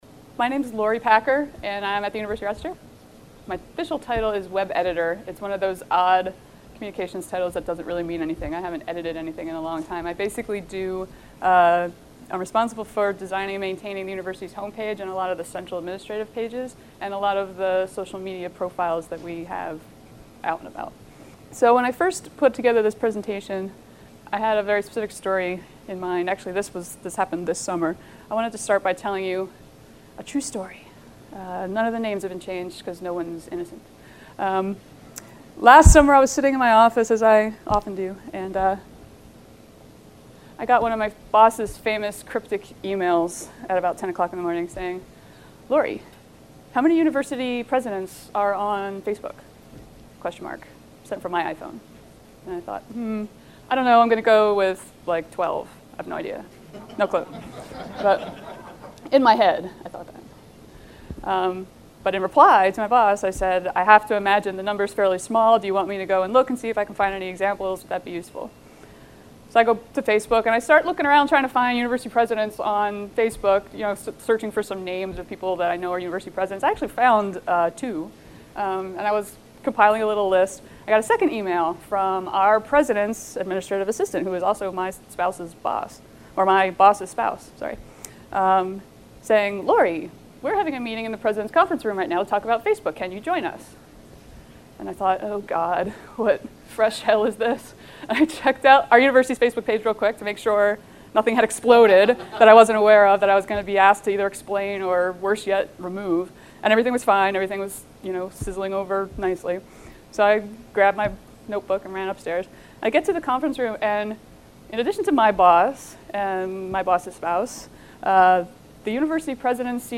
RED5 Red Stapler Track Salon H & I, 4th Floor Monday, 1:45 – 2:45 PM …and Facebook and YouTube, etc. Perhaps you've dabbled in social media personally and have some ideas on how your institution might participate.